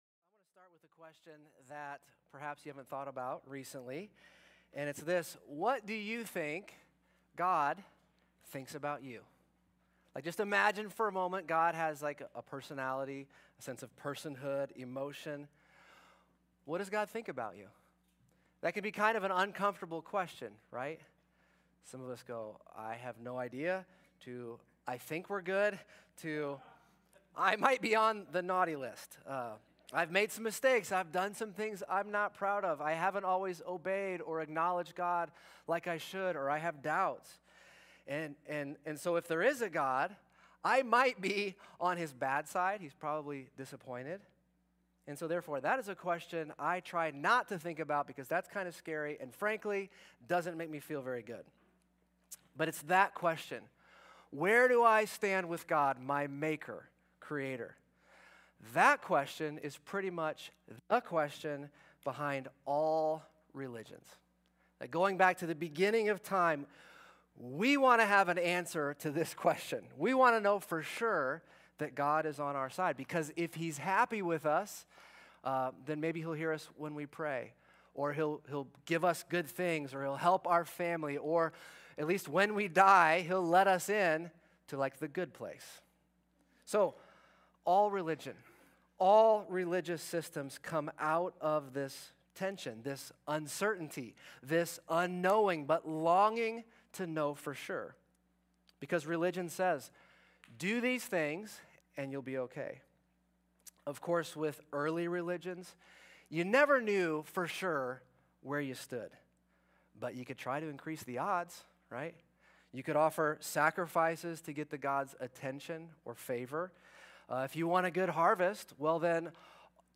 A message from the series "Why I Love Jesus."